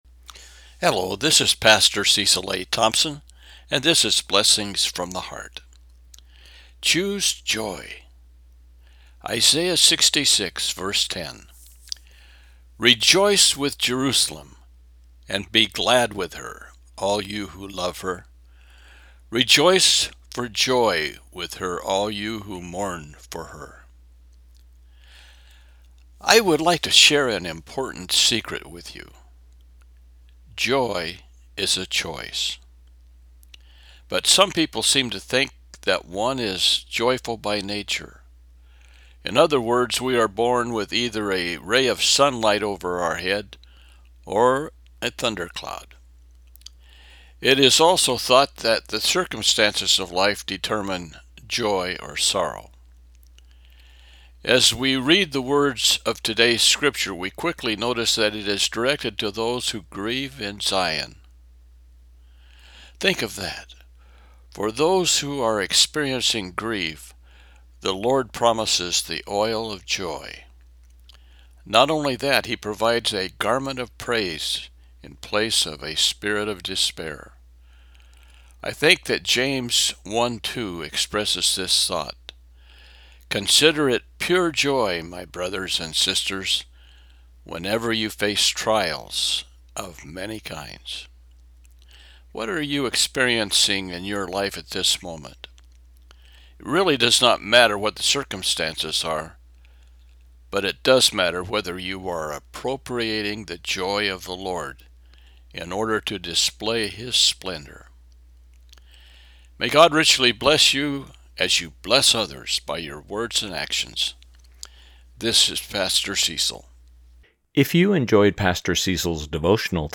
Isaiah 66:10 – Devotional